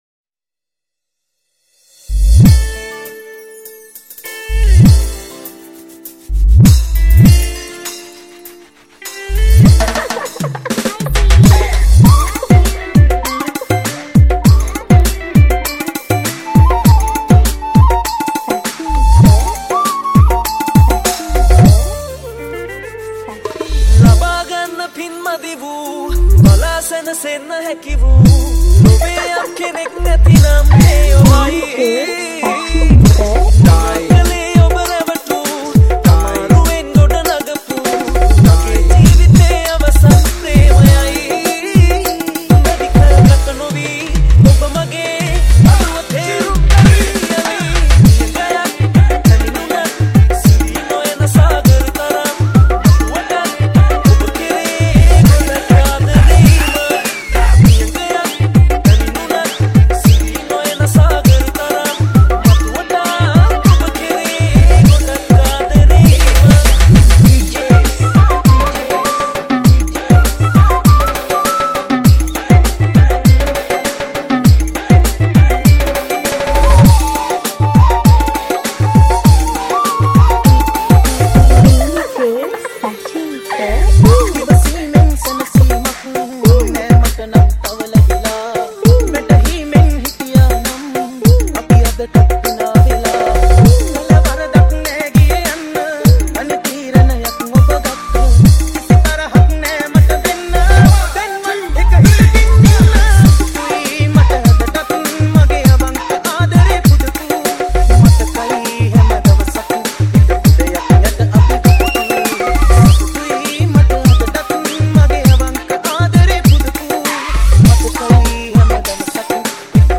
Category: Dj Remix